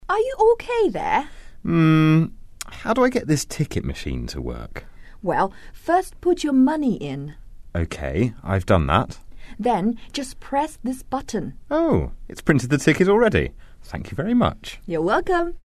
英语初学者口语对话第23集：这台售票机该怎么用？
english_41_dialogue_2.mp3